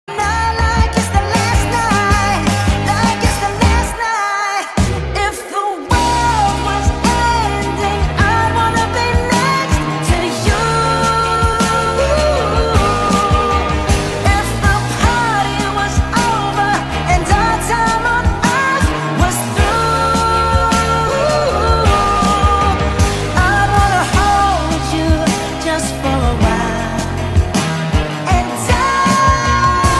Ringtone